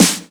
Snare OS 04.wav